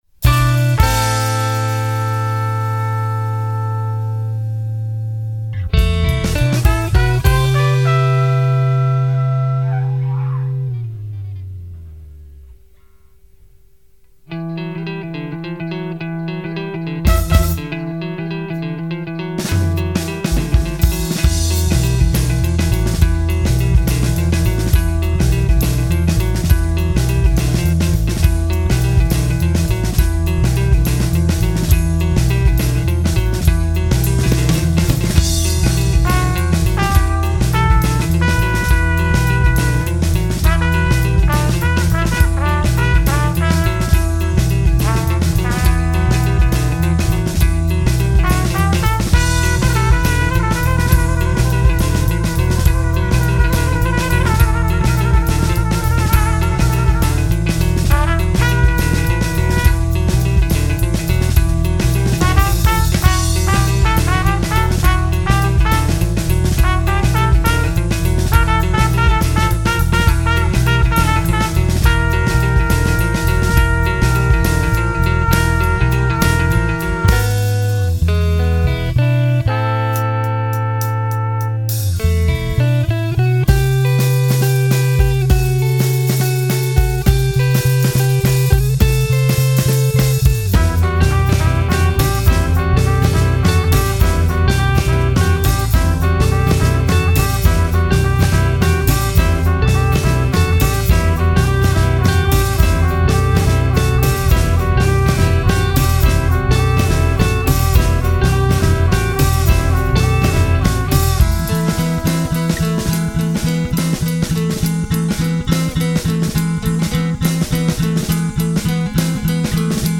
Rock, pop, instrumentale
Pièce musicale inédite